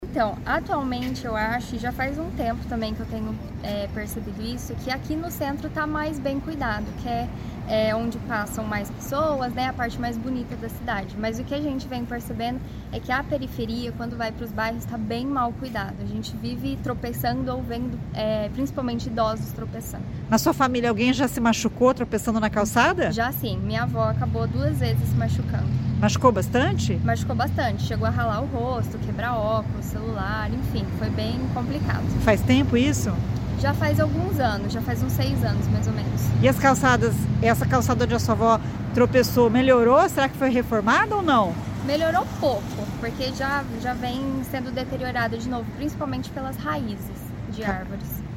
E a reportagem da CBN foi às ruas para saber a opinião das pessoas sobre a rampa metálica e sobre a conservação de calçadas de um modo geral.